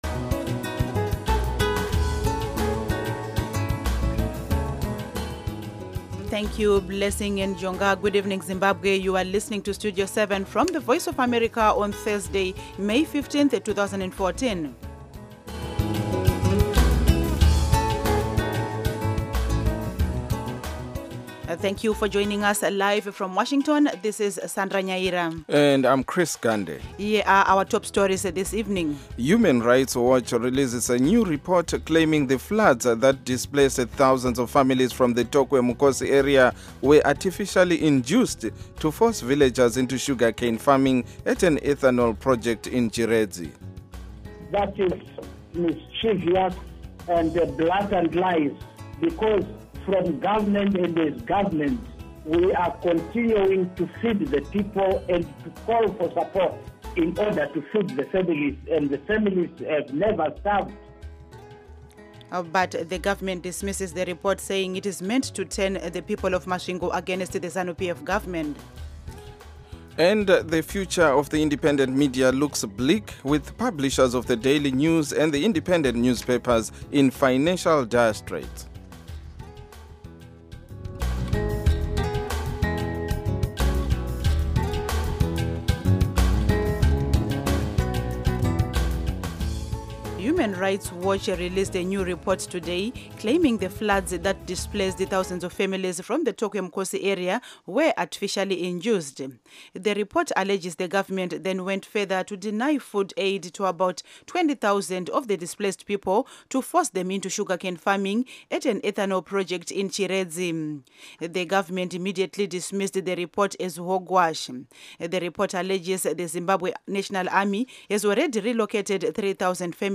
radio news